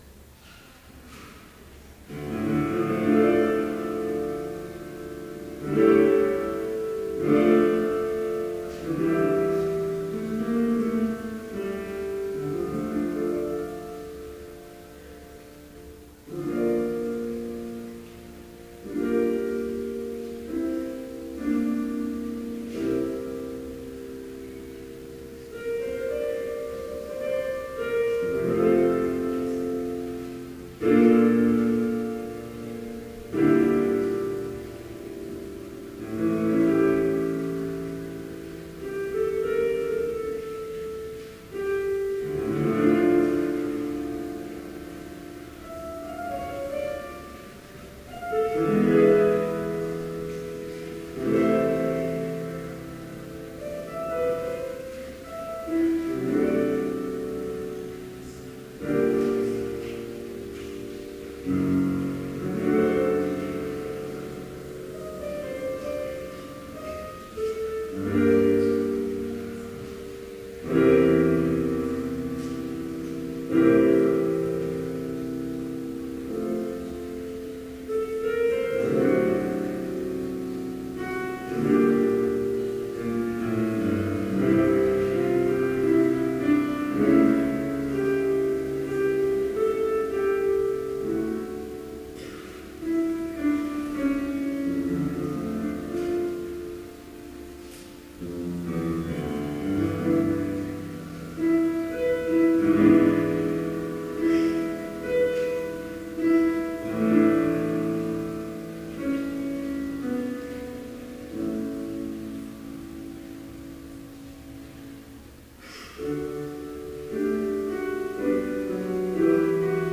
Complete service audio for Chapel - April 24, 2015